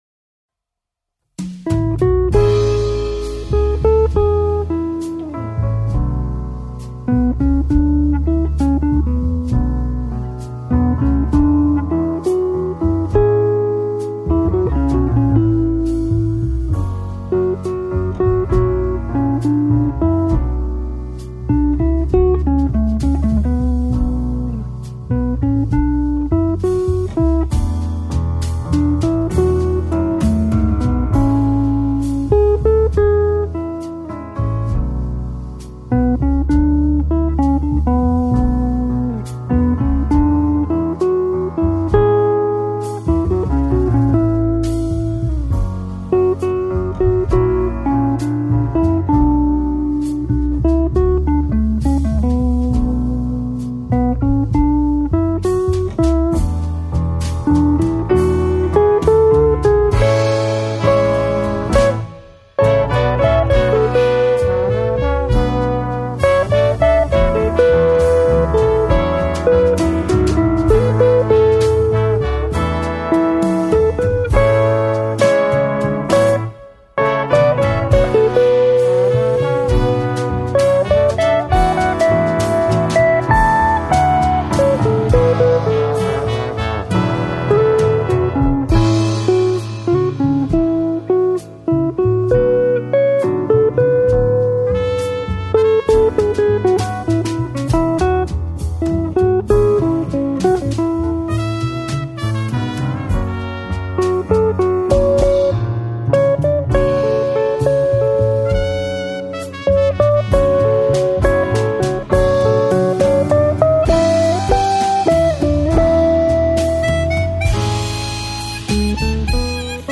contemporary jazz album